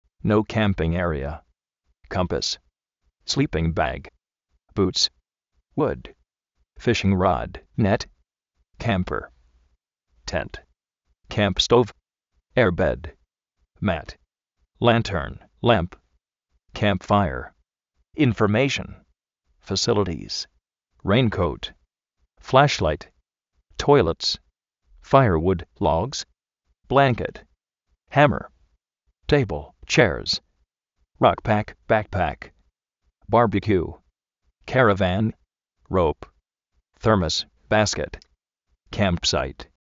Vocabulario en ingles, diccionarios de ingles sonoros, con sonido, parlantes, curso de ingles gratis
nóu kámping érea
kómpas
slí:ping bag
kámp-sáit